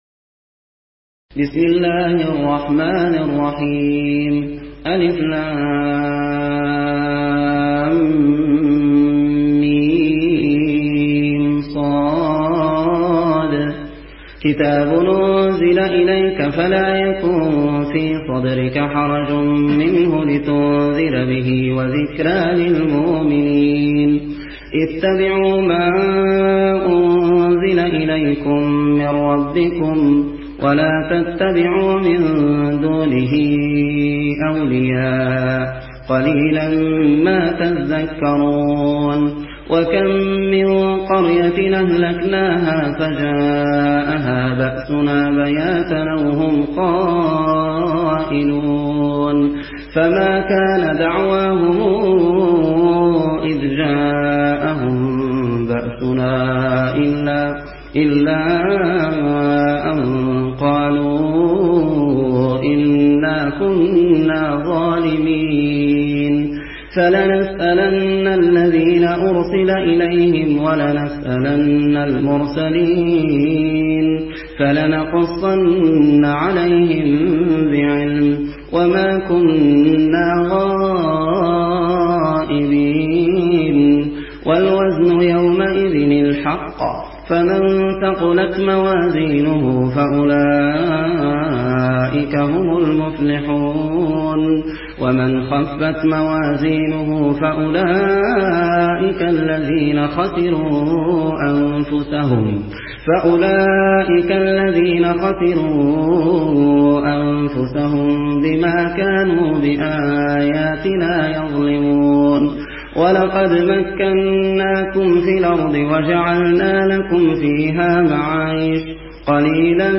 روایت ورش از نافع